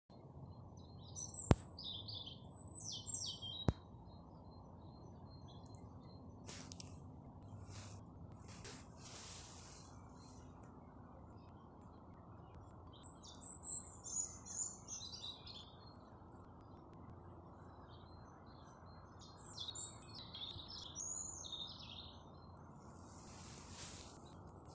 European Robin, Erithacus rubecula
Administratīvā teritorijaRīga
StatusSinging male in breeding season
NotesDzied pārvietojoties pagalma lielajos kokos.